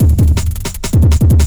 .wav 16 bit 44khz, Microsoft ADPCM compressed, mono,
Breakbeat 33k